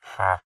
villager
haggle3.ogg